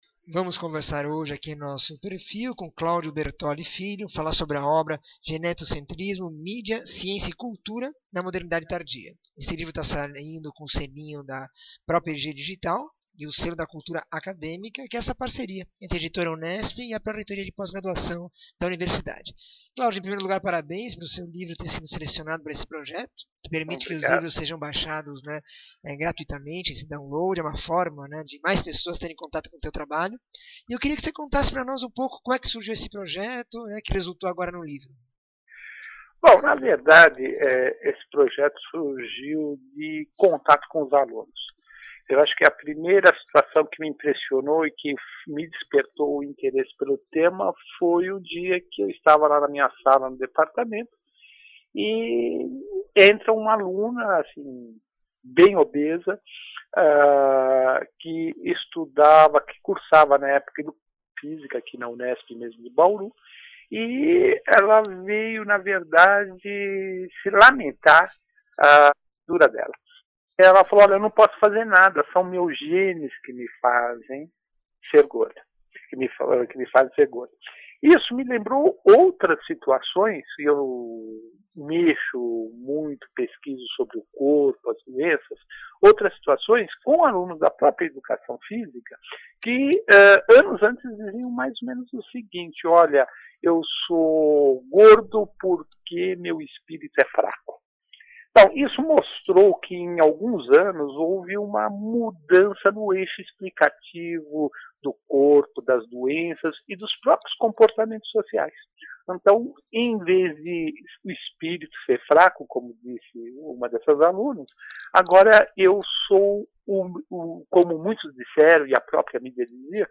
entrevista 1734
Entrevista